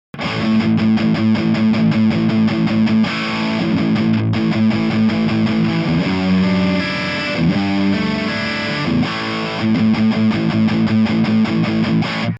Shapeの設定に大きな差をつけて再生してみましょう。
これはギターをソロで聴いたほうが分かりやすいかな…
ヘッドフォンで聴くとわかりやすくなると思うのですが、広がったり、中央に集まったりと動きのある効果が得られています。